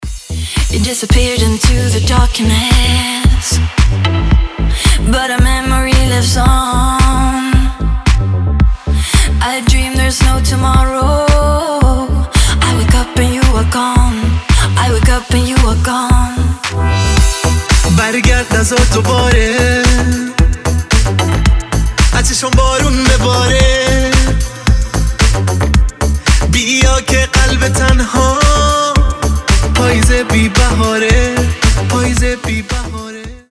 • Качество: 320, Stereo
мужской вокал
женский вокал
deep house
восточные мотивы
Club House
дуэт
красивый женский голос